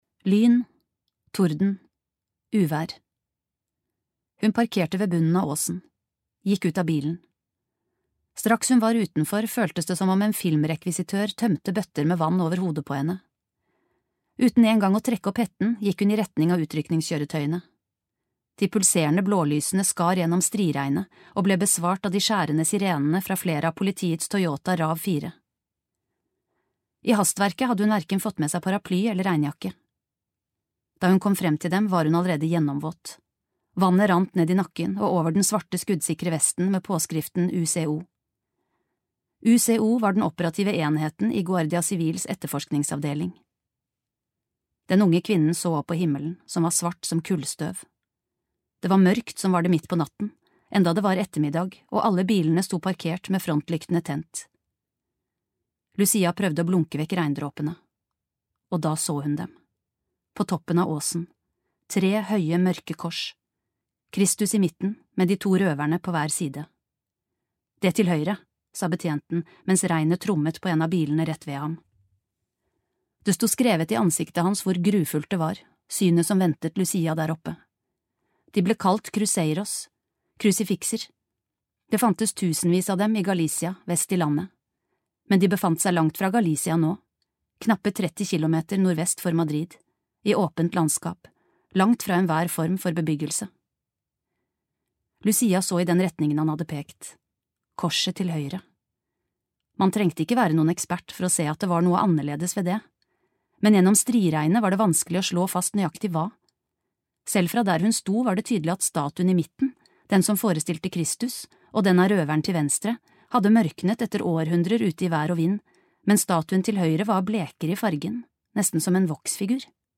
Lucia (lydbok) av Bernard Minier